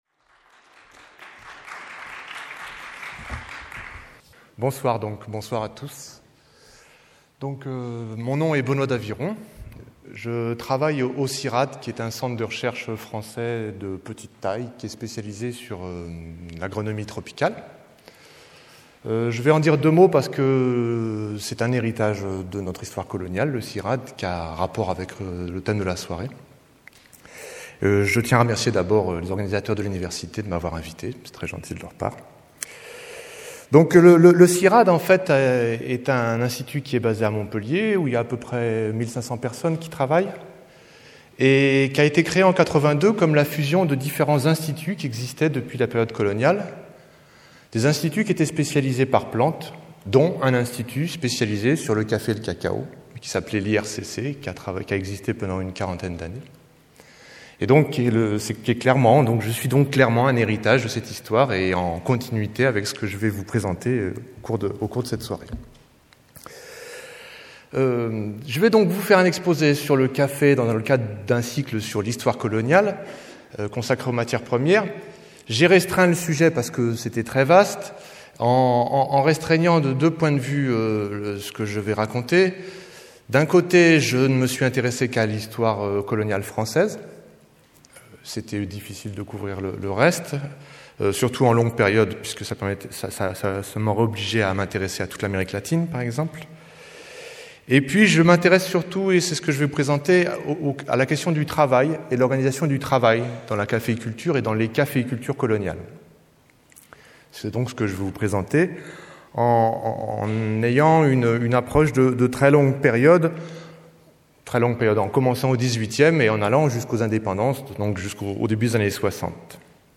Entre septembre 2009 et avril 2010, 15 conférences abordent l’histoire coloniale sous l’angle des matières premières.
Lieu : Théâtre Claude Lévi-Strauss